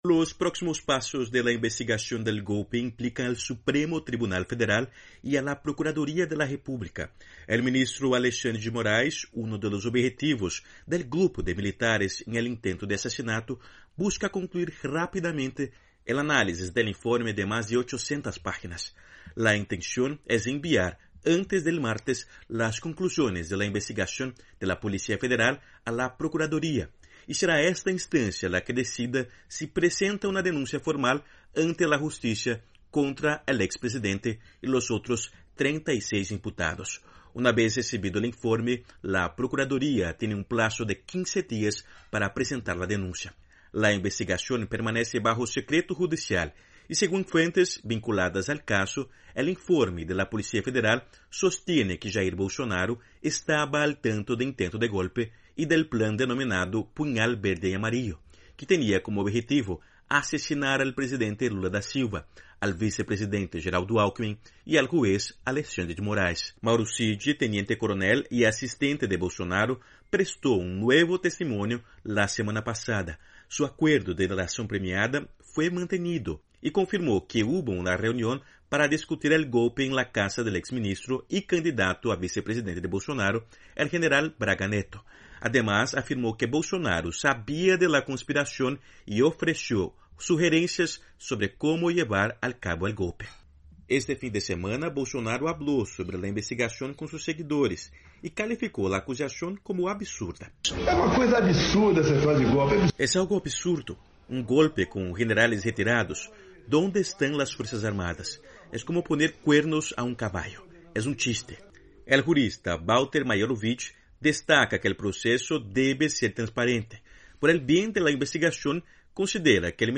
AudioNoticias
La Procuraduría General de Brasil recibirá la investigación sobre el intento de golpe de Estado que involucra a Jair Bolsonaro y otras 36 personas. Desde Brasil informa el corresponsal de la Voz de América